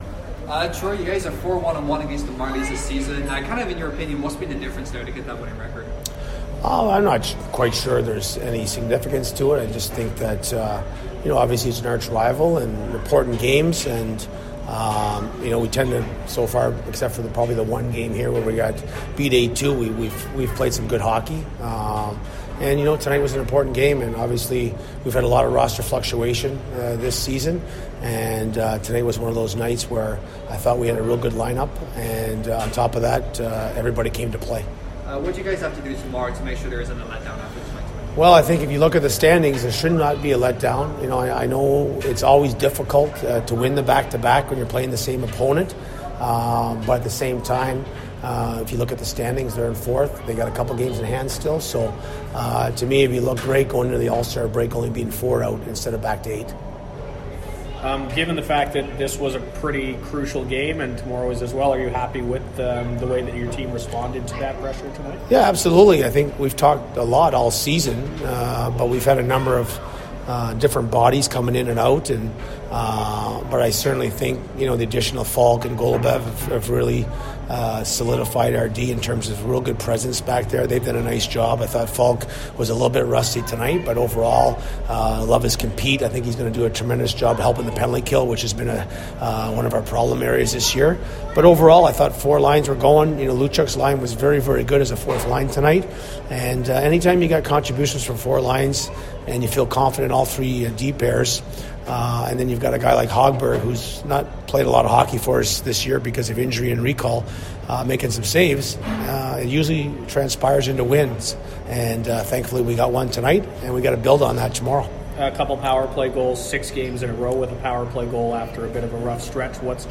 Post Game Interview: